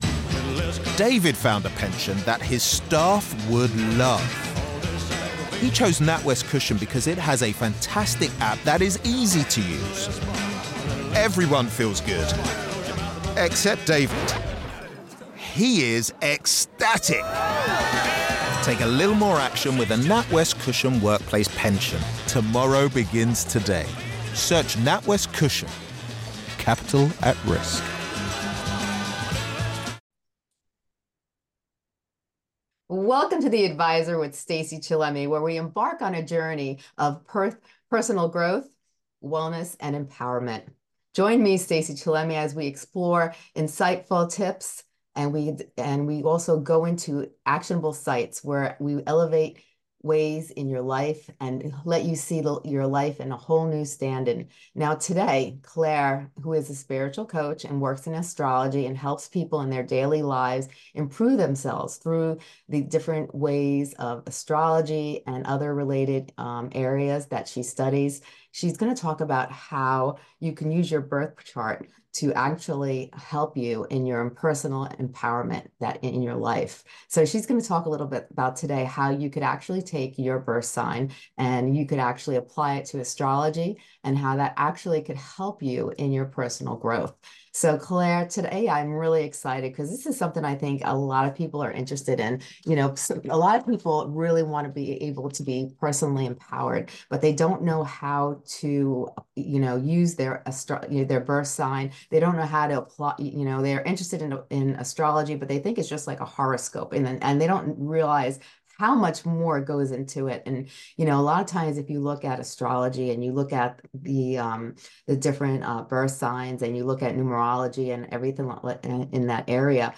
Learn how to navigate life’s challenges, embrace your strengths, and achieve personal fulfillment through the guidance of astrology in this illuminating conversation that could change your life for the better!